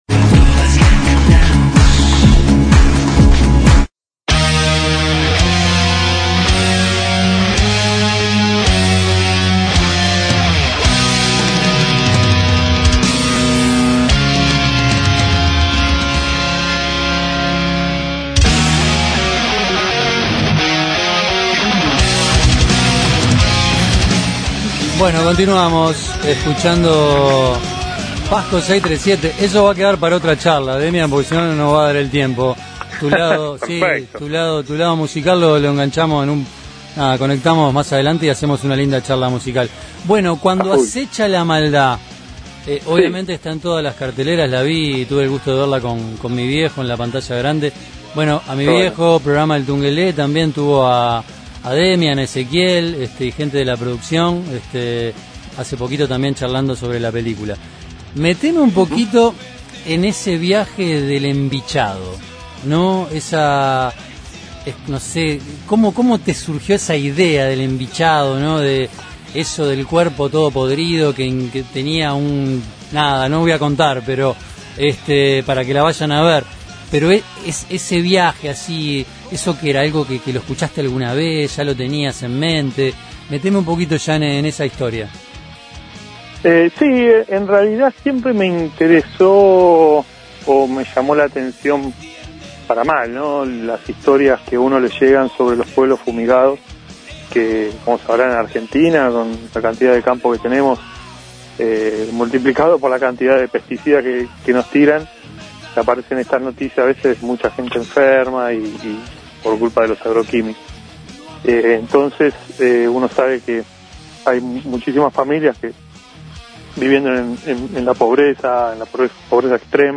En charla rioplatense
Demian Rugna se hizo presente en Rock al rock experience.